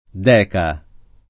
[ d $ ka ]